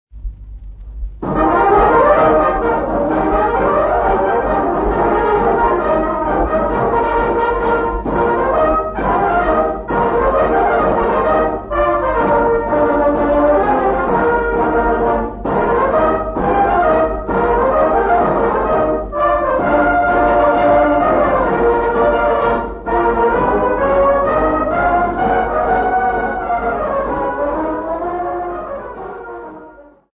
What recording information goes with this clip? Africa Democratic Republic of Congo city not specified f-cg field recordings